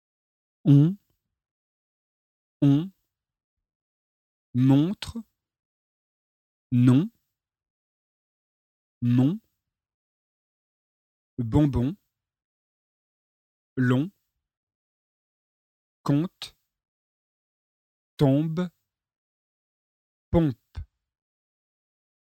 II. ON, OM : PRONONCIATION & EXPLICATION
pronunciation nasale francais